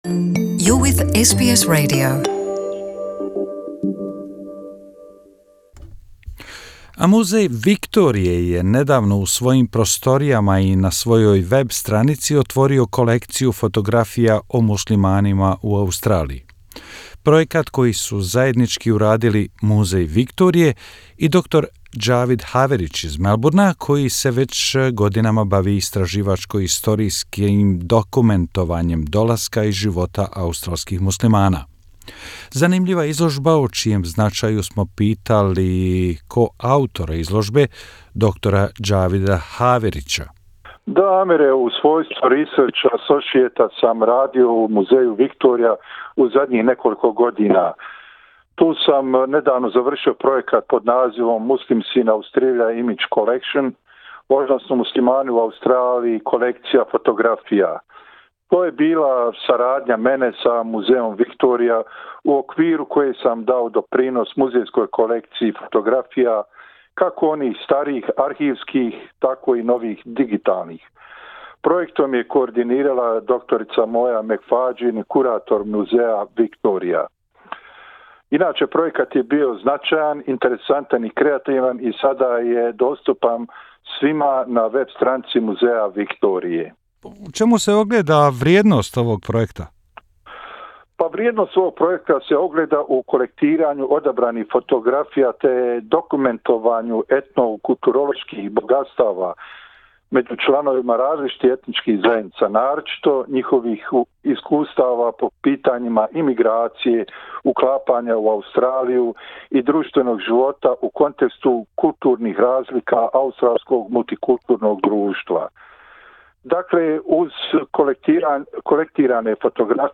U razgovoru za naš radio